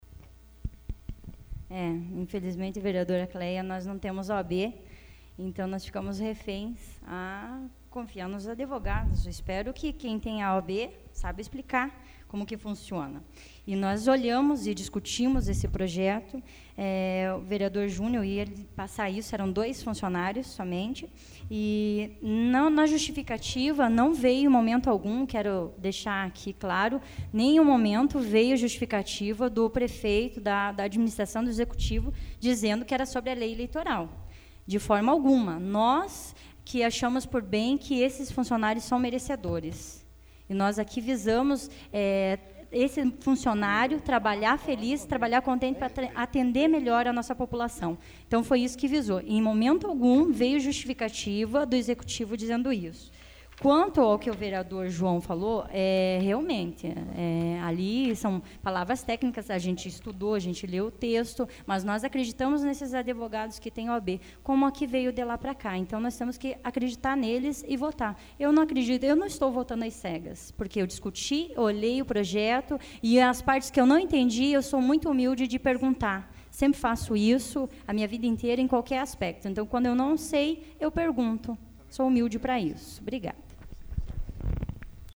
Discussão do Projeto AVULSO 25/03/2014 Fernanda do Nelsão